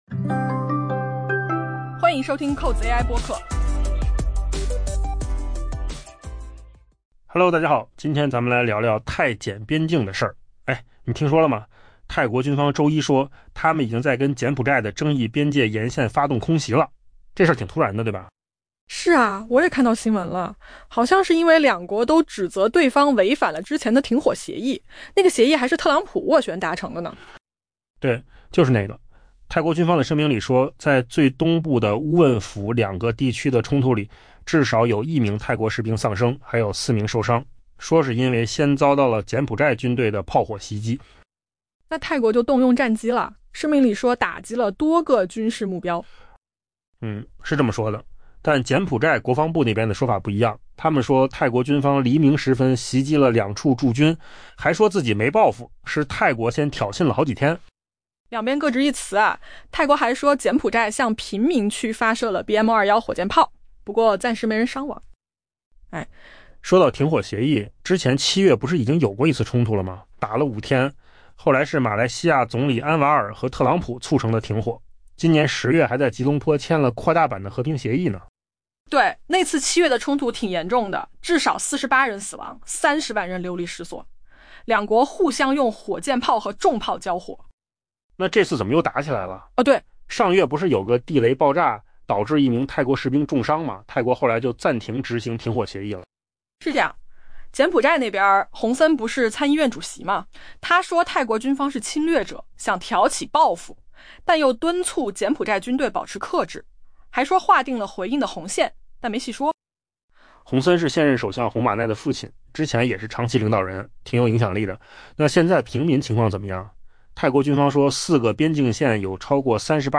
AI 播客：换个方式听新闻 下载 mp3 音频由扣子空间生成 泰国军方周一表示， 泰国已在其与柬埔寨的争议边界沿线发动空袭。